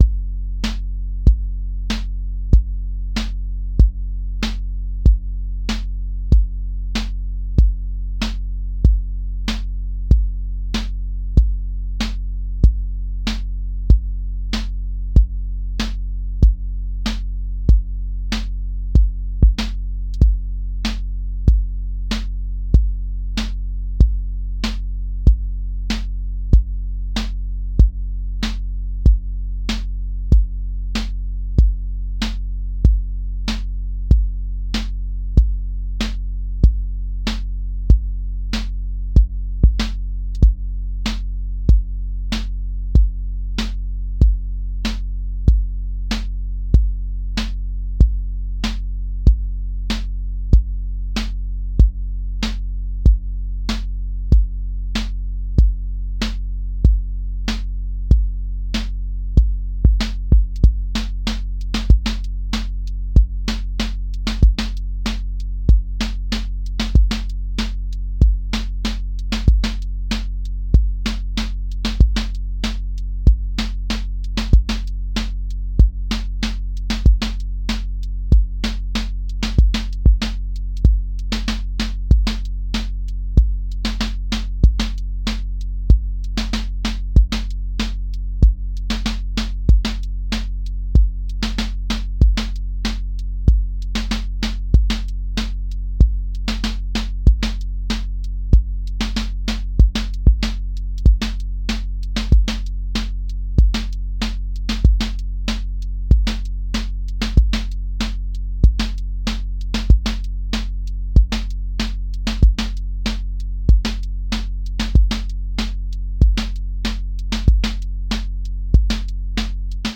QA Listening Test boom-bap Template: boom_bap_drums_a
• voice_kick_808
• voice_snare_boom_bap
• voice_hat_rimshot
• voice_sub_pulse
A 120-second boom bap song with recurring patterns, a lifted bridge, a grounded return, and section recombination that feels like an actual song rather than one loop